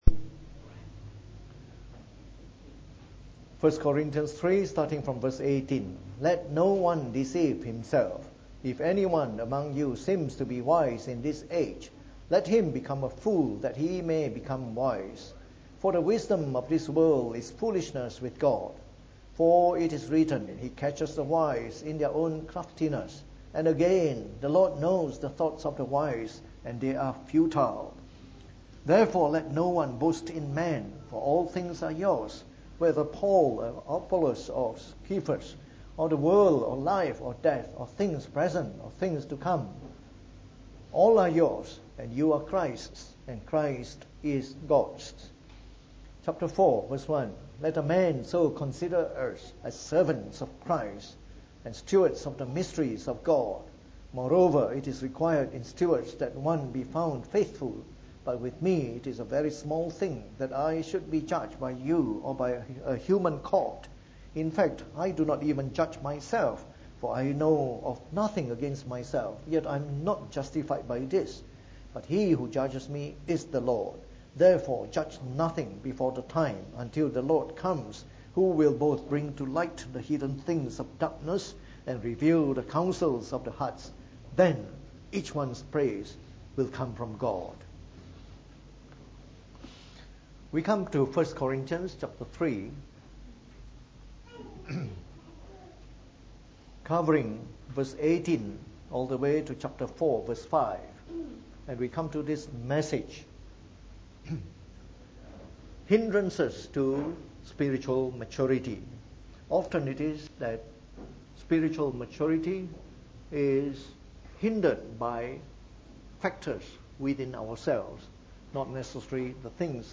From our series on 1 Corinthians delivered in the Evening Service.